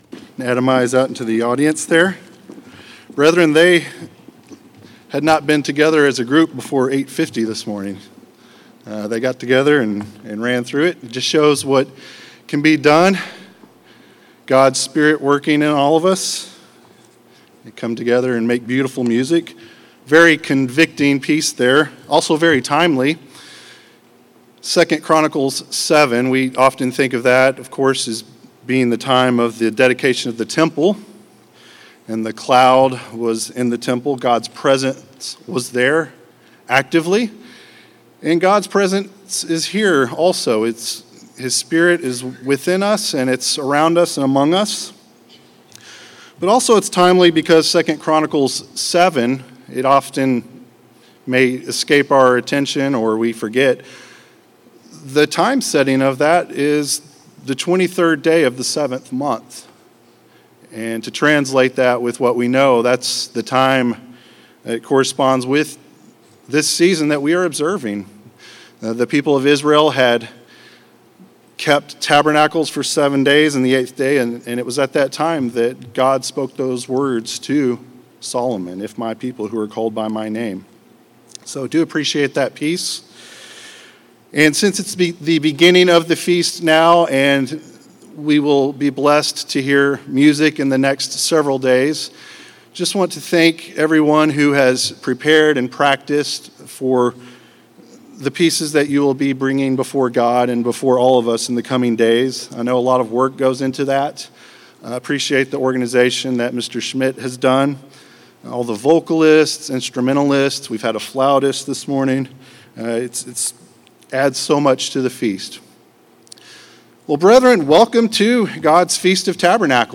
This sermon was given at the Branson, Missouri 2021 Feast site.